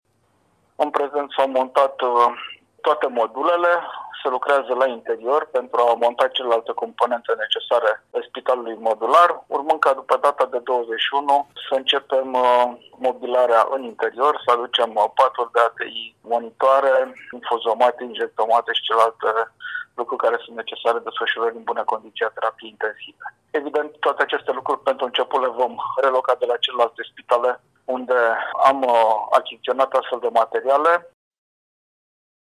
Amenajarea spitalului modular se apropie de final, a declarat preşedintele Consiliului Judeţean. Unitatea medicală modulară va avea 28 de paturi pentru terapie intensivă şi este amenajată în curtea Spitalului Judeţean Braşov.